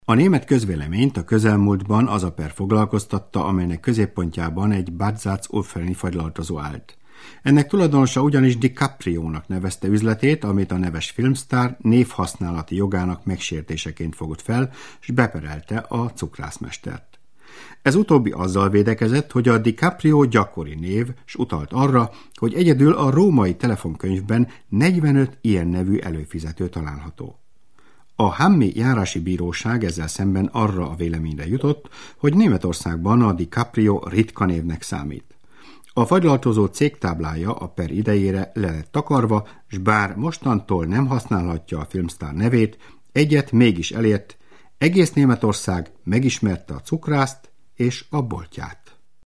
ungarischer Profi Sprecher Ausbildung: Hochschulstudium in Ungarn (Philologie) Tätigkeiten: Fremdsprachenredakteur, Sprecher, Regisseur, Moderator, Übersetzer mit journalisticher Textbearbeitung und eingetragener Dolmetscher Referenzenauszug: Allianz Versicherungen, BASF, Deutsche Welle, Ford, Hösch, KHD, Langenscheidt KG, Opel, Paul Hartmann AG, Samsung, Sony und WDR Arbeitsgebiet: Deutschland, Belgien, Niederlande, Schweiz Italien und Ungarn Produktionen: Trailer, Imagefilm, TV Film, TV Werbung, Funkwerbung, Dokumentarfilm, Lehrfilm, Hörbuch
Sprechprobe: Werbung (Muttersprache):
hungarian voice over artist